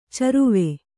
♪ caruve